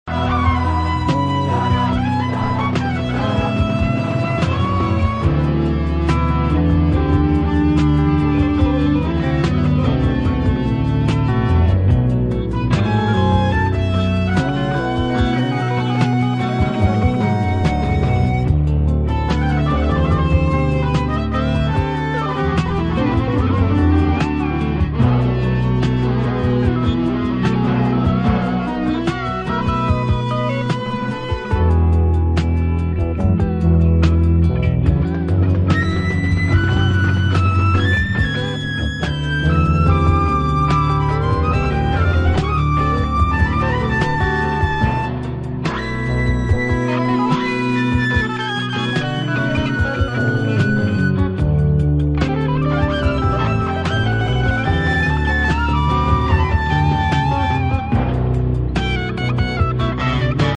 lead guitarists
bass
keyboard
Funk / soul
P funk